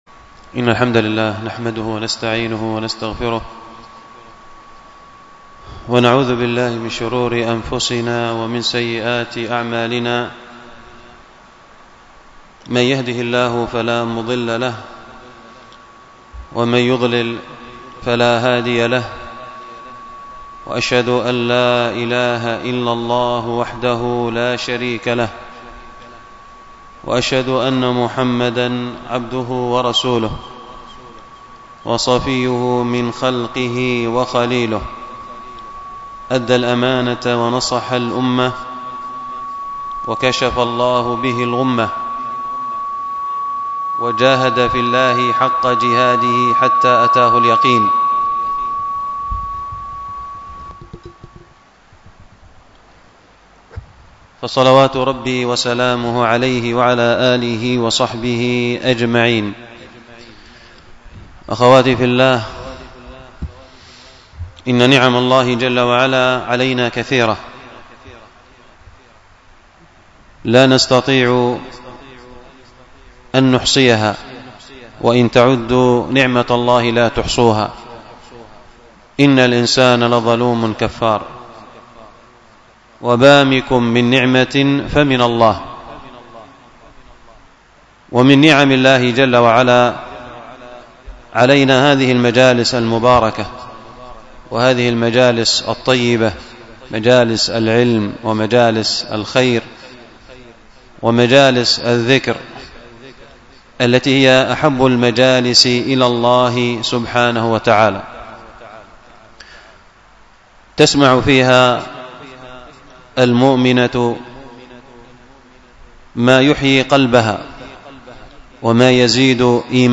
المحاضرة بعنوان السلاح الذي لا يقهر، والتي كانت بمسجد ابن عباس بزغفه بالشحر 27 محرم 1446هـ الموافق 2 أغسطس 2024م